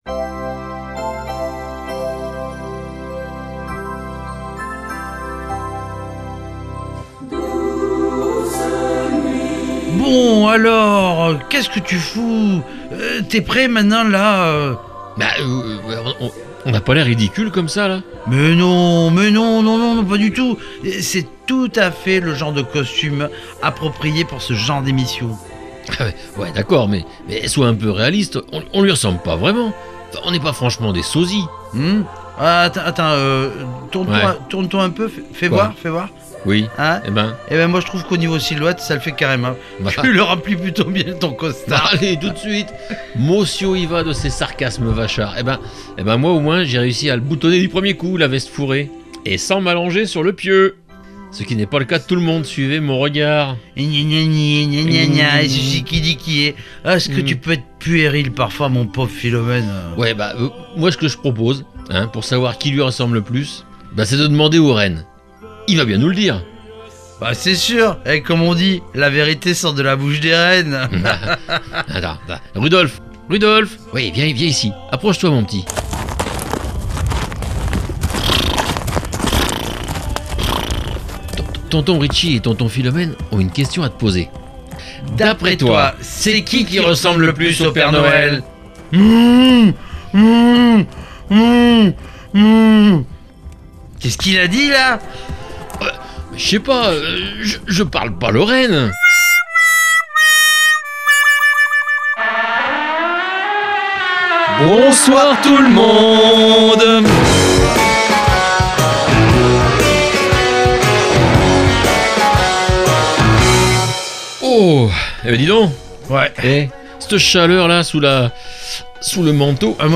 Cette année , c’est plutôt « No Hell 2023 » et donc plutôt « PARADISE » pour les Rockeuses et Rockers en tous genres !